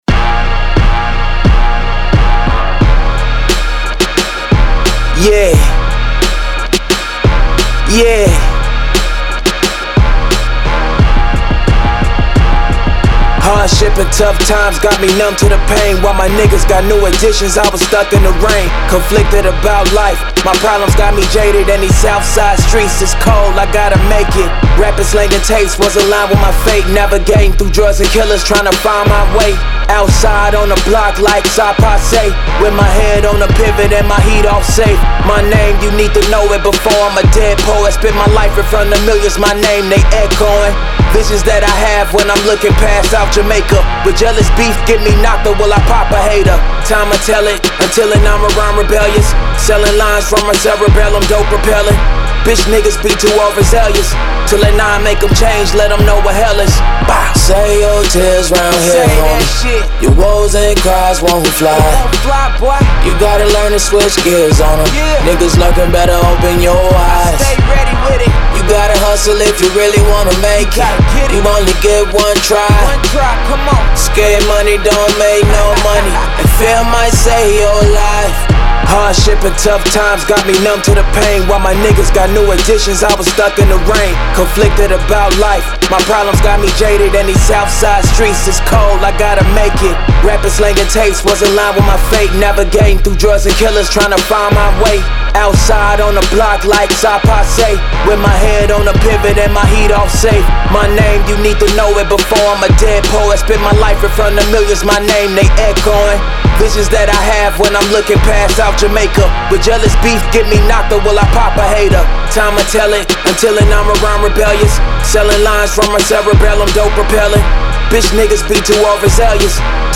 Hip Hop, 90s
Ab Major